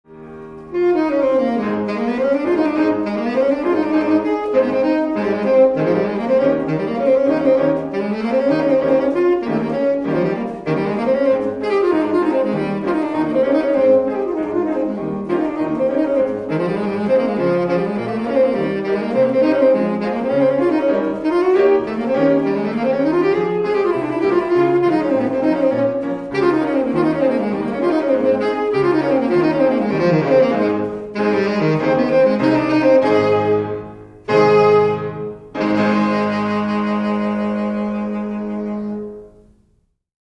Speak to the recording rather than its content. A few examples of my own playing are offered for your perusal.